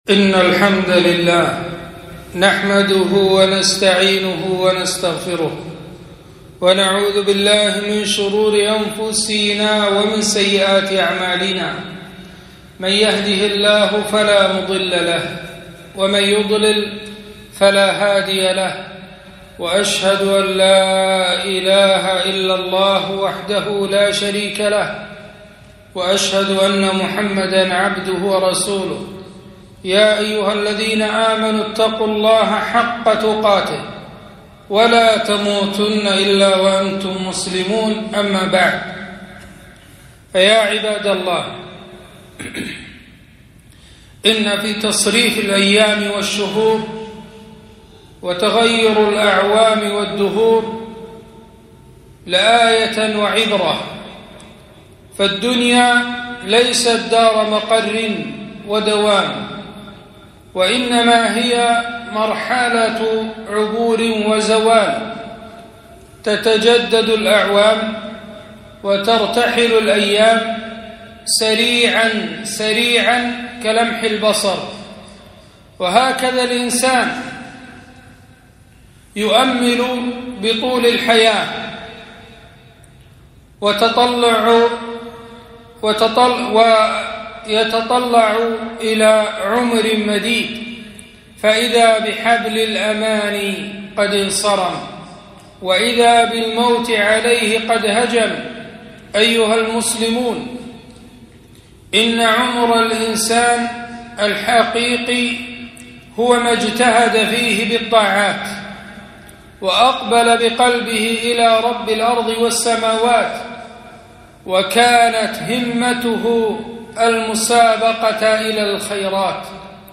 خطبة - فضل شهر محرم وما يشرع فيه